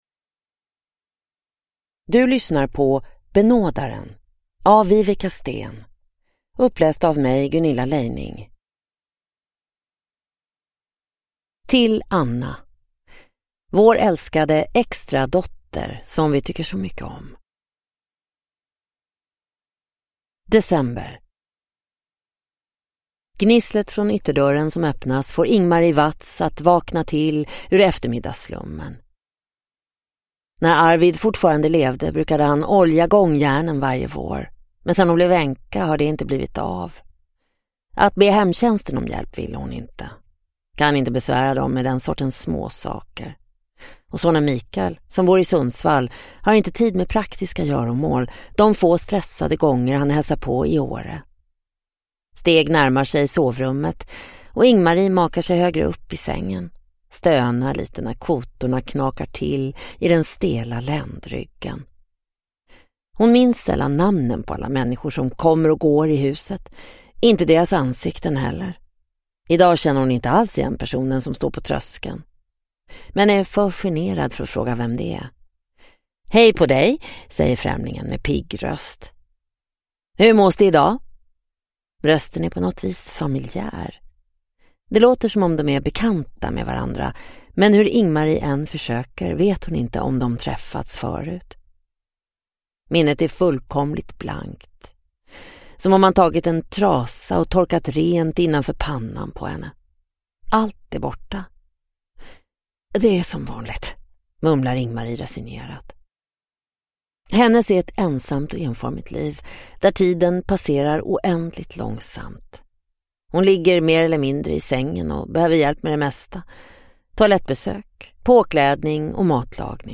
Benådaren – Ljudbok